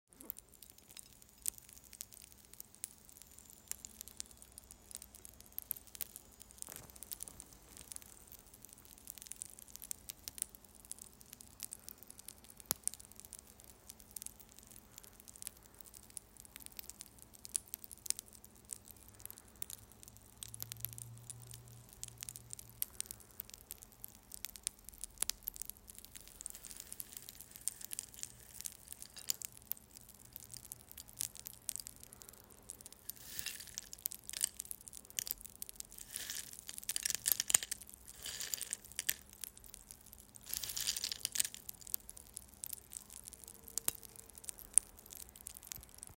en Paying attention to the sounds, this specific household sound made an impact on me and decided to record it.
This little sprinkles reminded me of neural connections and improvising on this was also a very beautiful and calming experience
en Crack
en Boiling
en Burning
en Drizzle
en Home, Tbilisi, Georgia